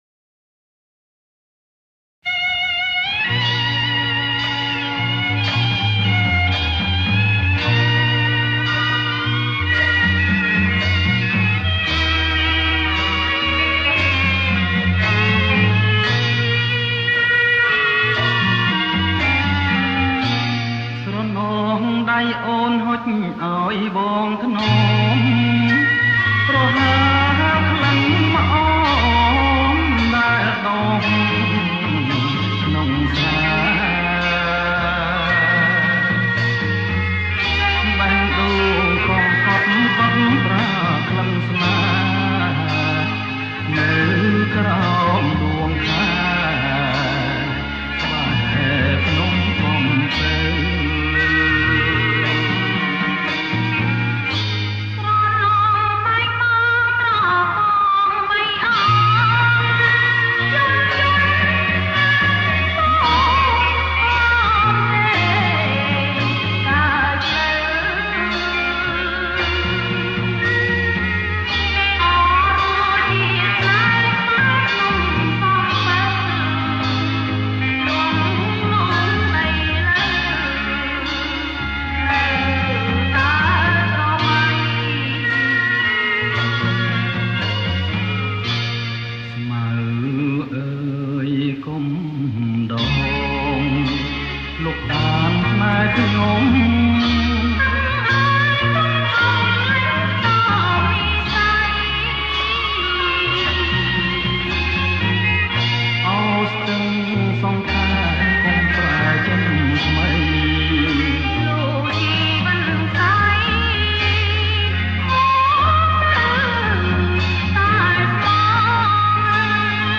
ប្រគំជាចង្វាក់